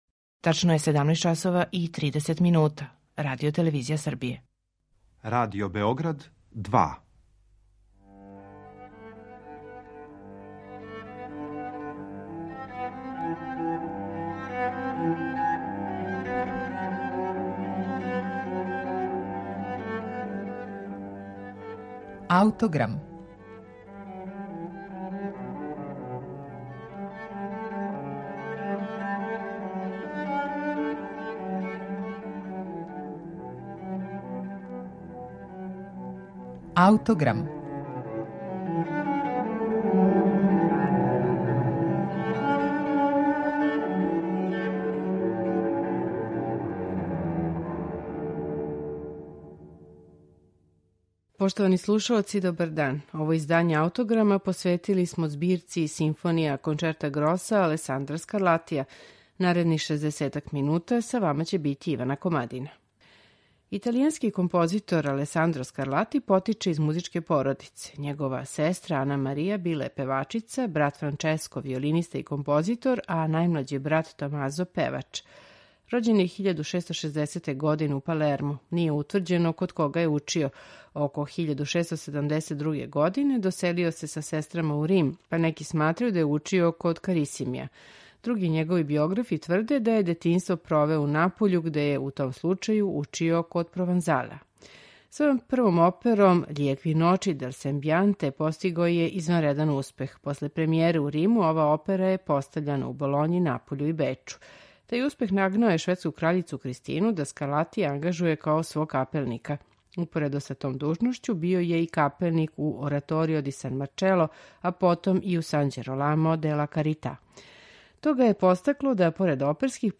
Првог јуна 1715. године почео је да пише серију од 12 симфонија за гудачки оркестар, континуо и променљив састав дувачких инструмента.
флауте
труба
обоа и камерни оркестар I musici .